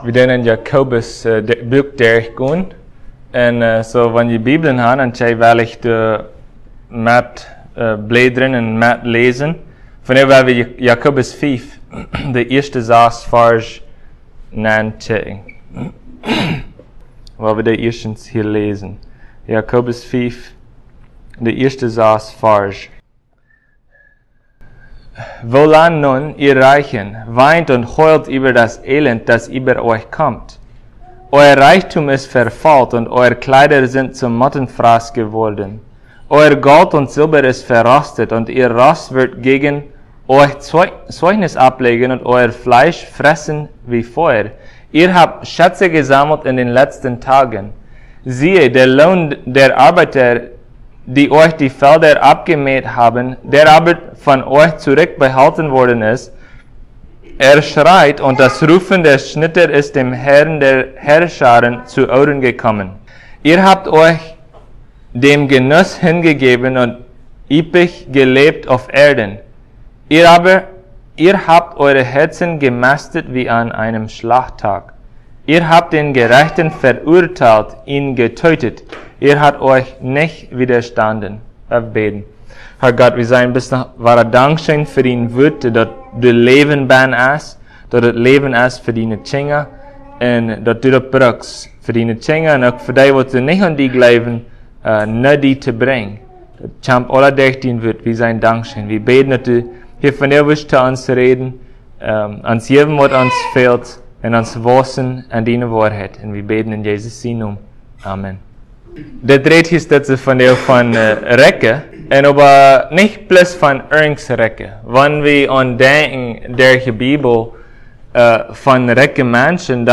Passage: James 5:1-6 Service Type: Sunday Plautdietsch « Love